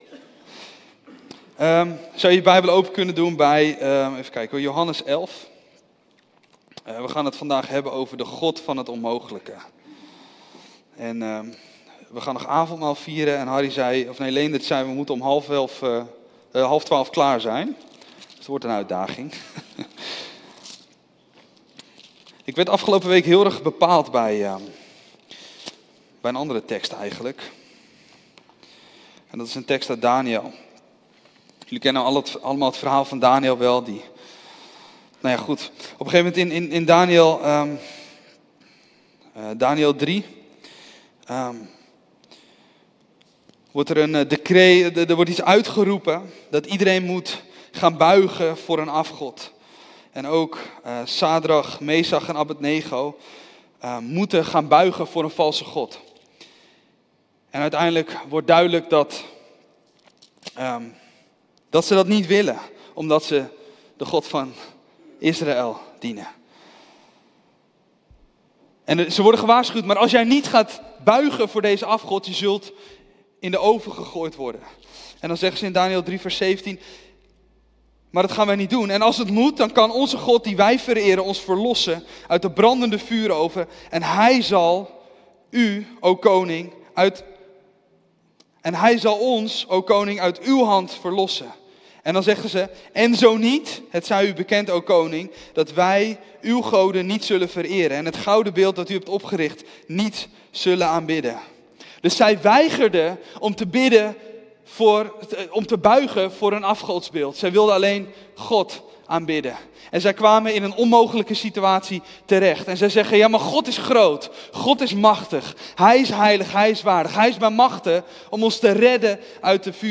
De preek